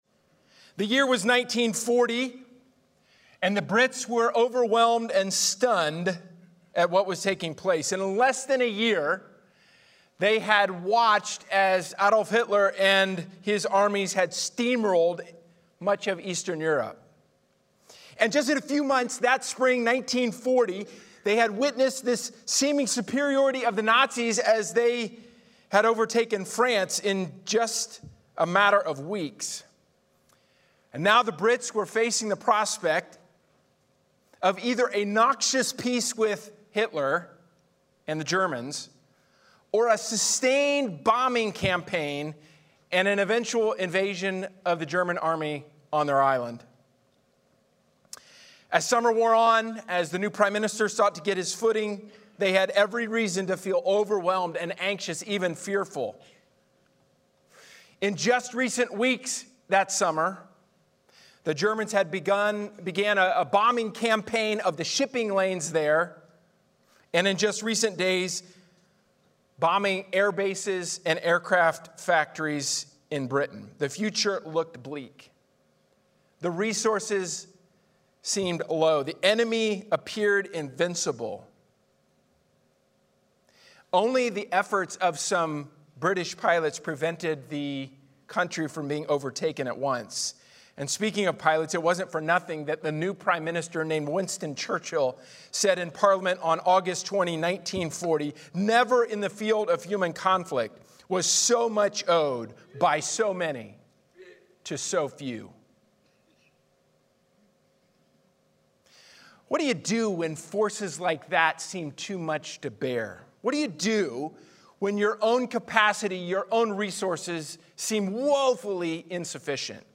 All Sermon Series • Grace Polaris Church